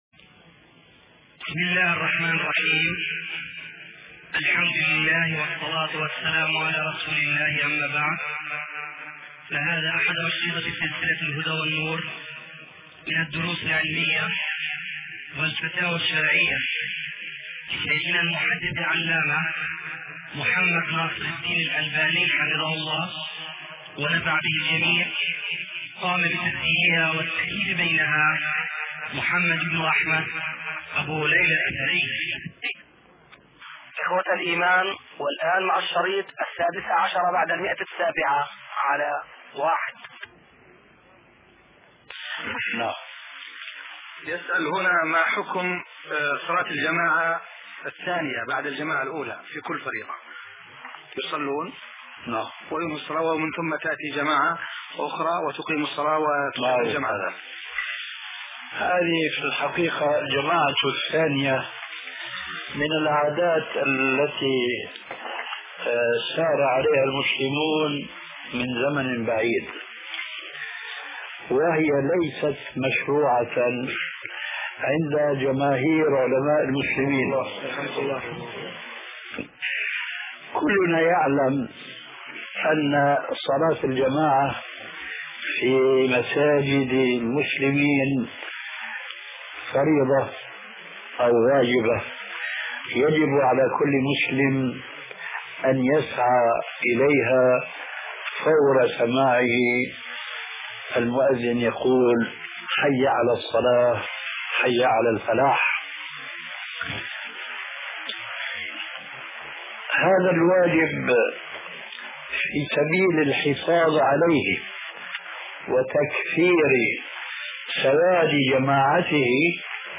شبكة المعرفة الإسلامية | الدروس | حكم صلاة الجماعة الثانية |محمد ناصر الدين الالباني